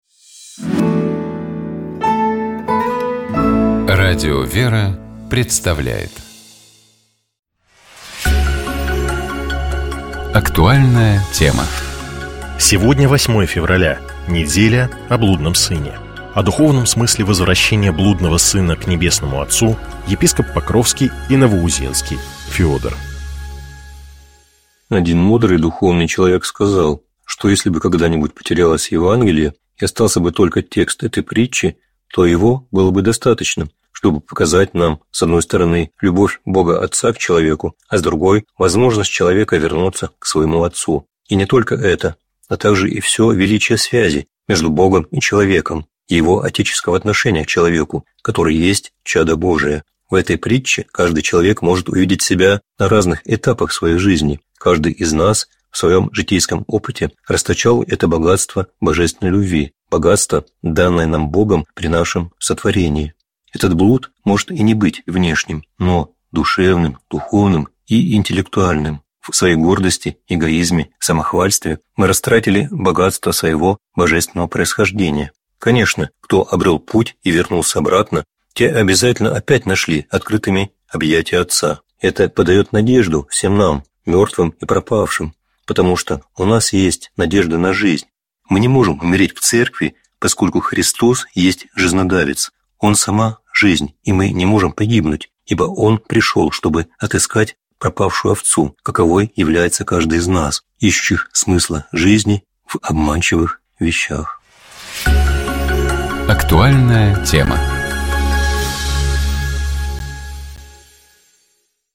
О духовном смысле возвращения блудного сына к Небесному Отцу — епископ Покровский и Новоузенский Феодор.